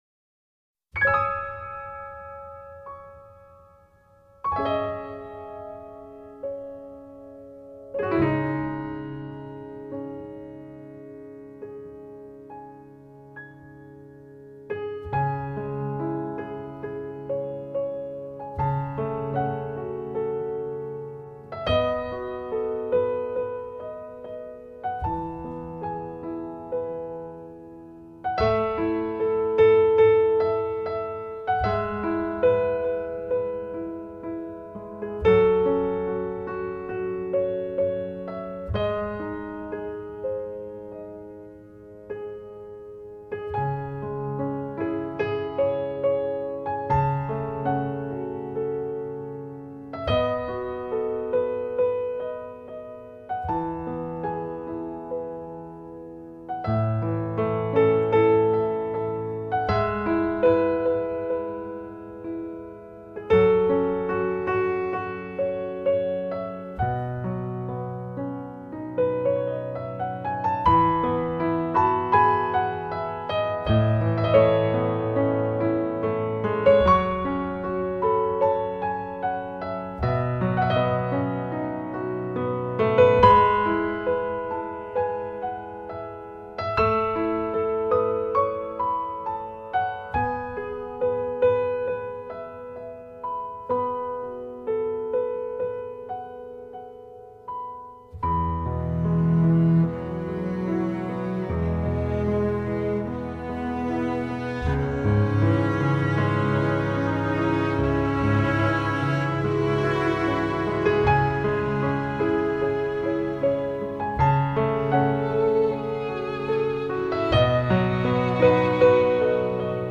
夢幻迷人的音樂